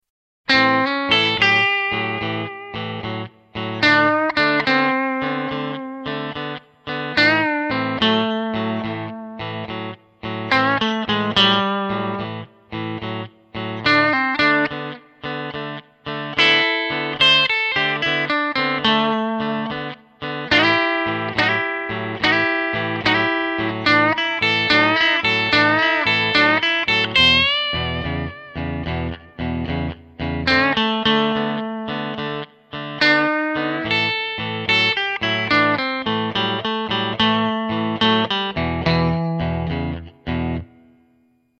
Tablatures pour Guitare